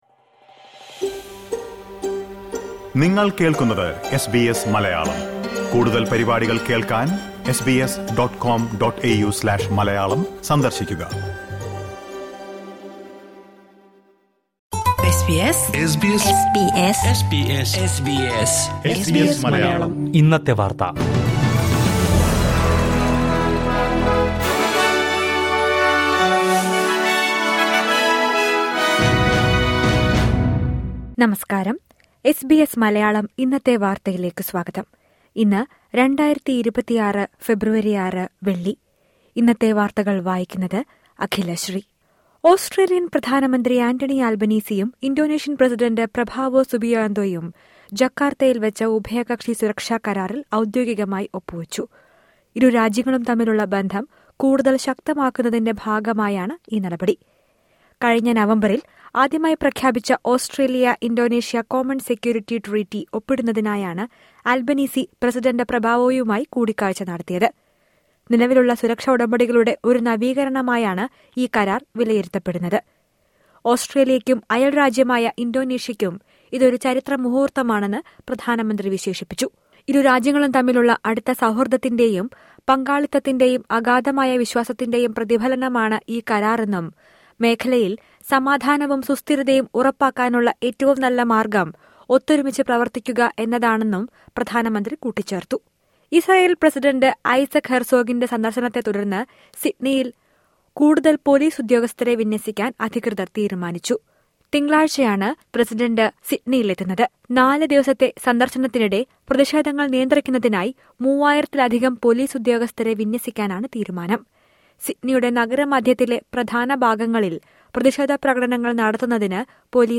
2026 ഫെബ്രുവരി ആറിലെ ഓസ്ട്രേലിയയിലെ ഏറ്റവും പ്രധാന വാർത്തകൾ കേൾക്കാം...